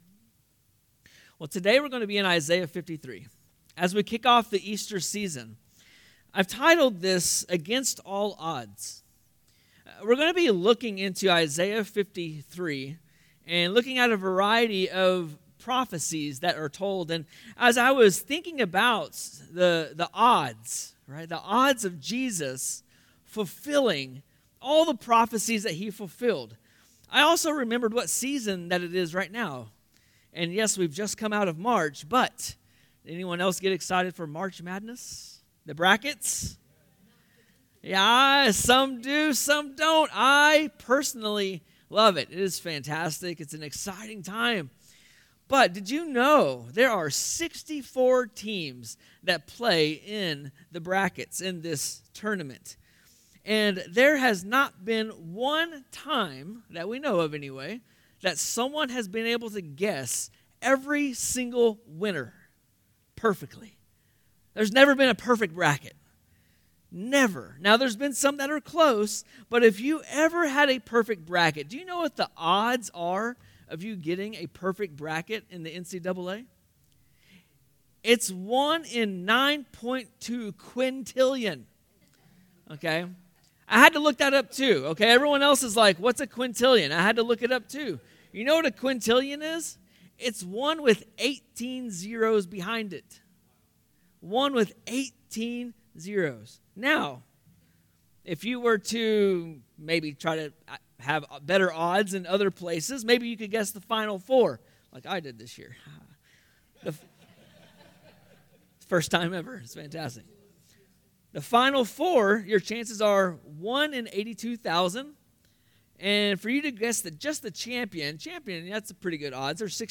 Sermons | Gainesville Bible Church